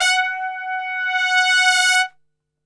F#3 TRPSWL.wav